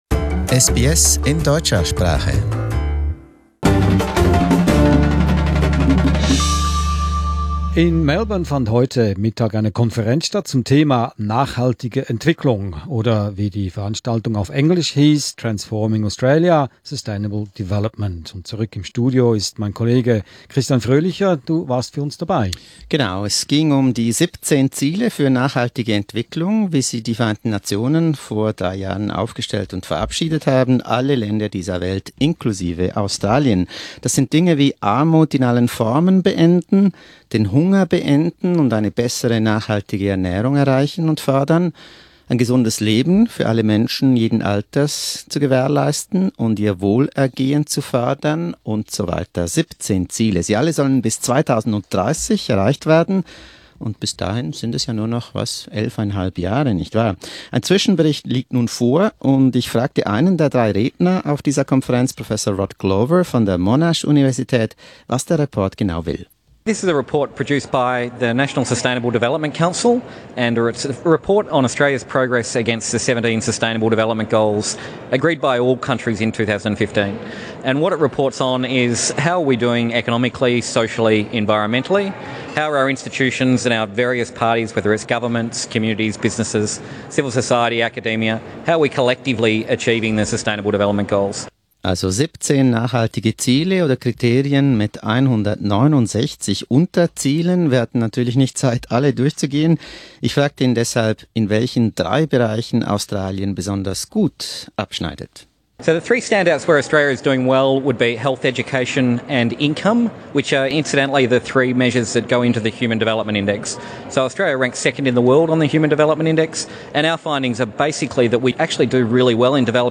Eine Konferenz in Melbourne am 5. September, organisiert von CEDA, präsentierte erstaunliche Resultate für Australien.